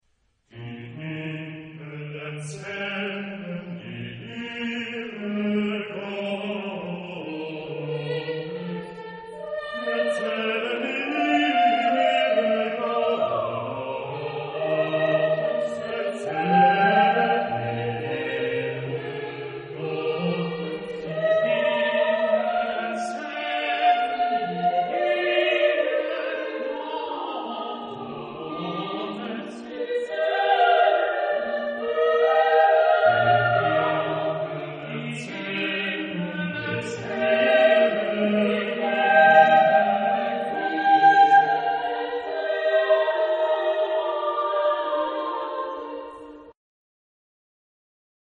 Genre-Style-Form: Sacred ; Motet ; Psalm
Type of Choir: SSATB  (5 mixed voices )
Tonality: E flat major